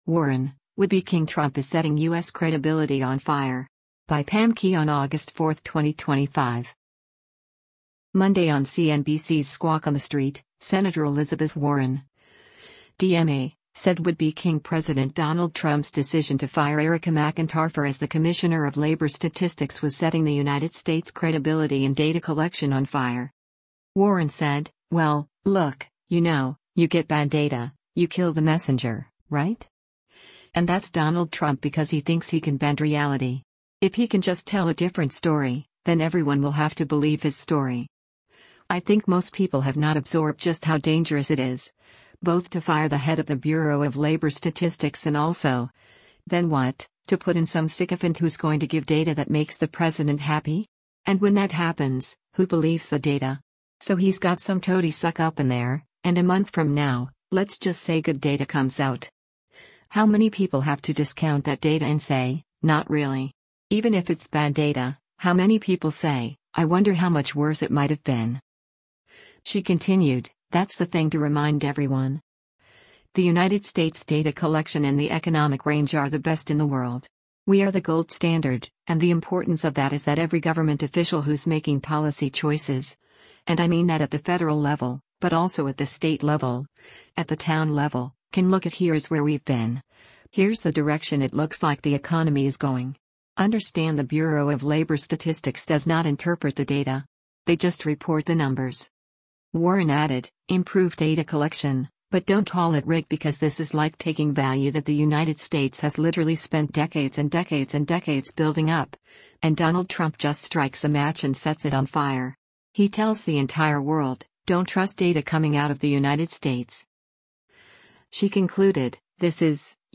Monday on CNBC’s “Squawk on the Street,” Sen. Elizabeth Warren (D-MA) said “would-be king” President Donald Trump’s decision to fire Erika McEntarfer as the commissioner of labor statistics was setting the United States’ credibility in data collection “on fire.”